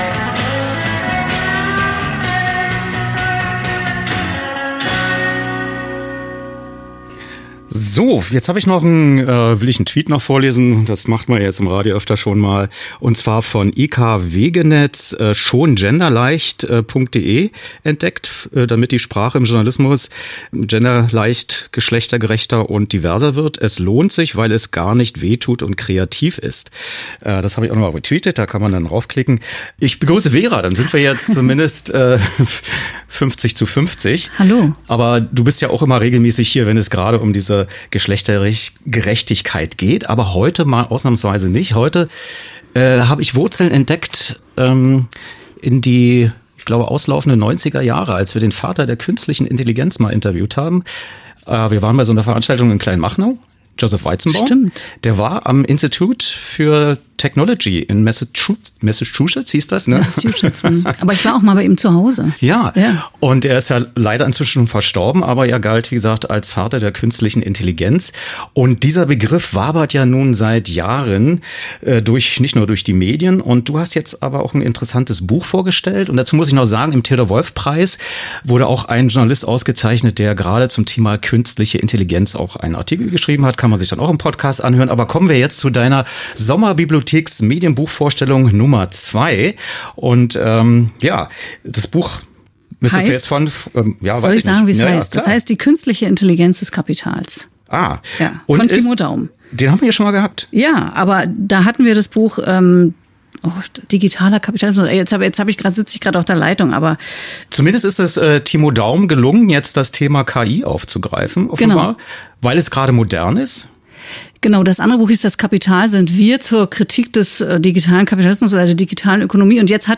Studiogespräch zum Buch
Senderegie radioeins, Potsdam-Babelsberg, Medienstadt